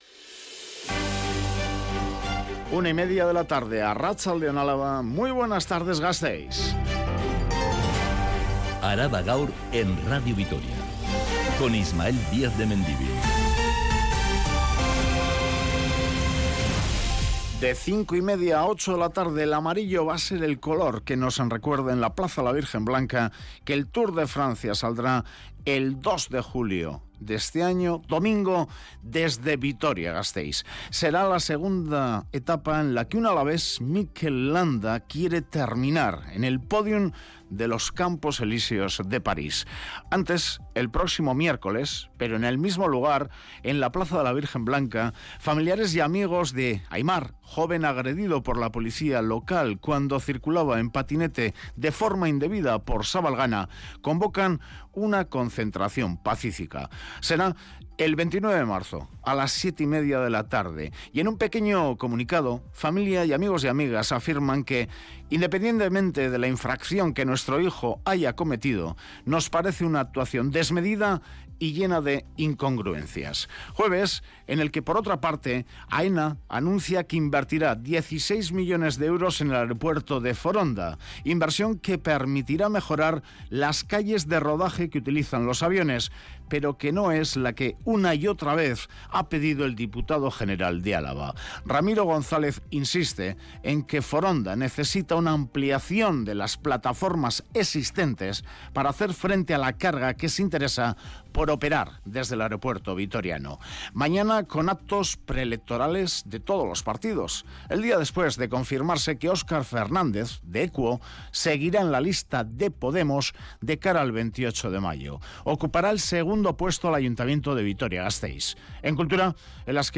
Radio Vitoria ARABA_GAUR_13H Araba Gaur (Mediodía) (23/03/2023) Publicado: 23/03/2023 14:58 (UTC+1) Última actualización: 23/03/2023 14:58 (UTC+1) Toda la información de Álava y del mundo. Este informativo que dedica especial atención a los temas más candentes de la actualidad en el territorio de Álava, detalla todos los acontecimientos que han sido noticia a lo largo de la mañana.